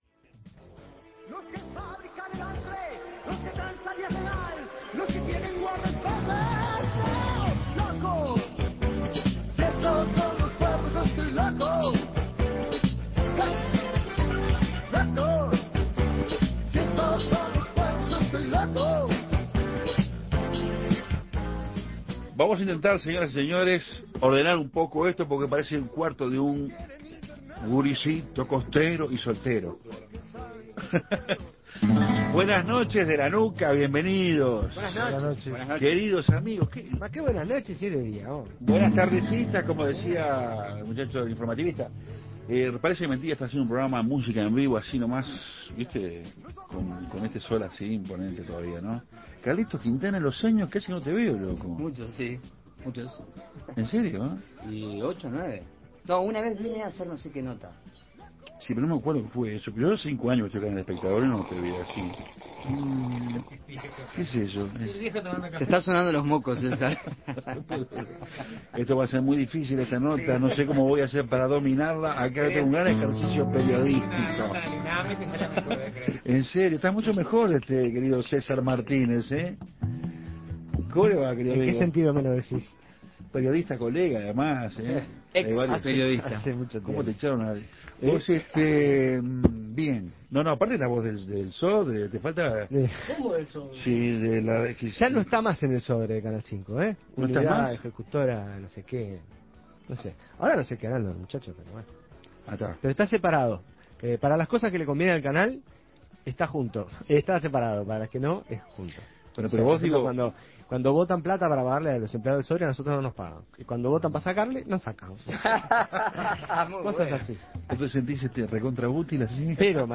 guitarra
teclados
bajo
batería
percusión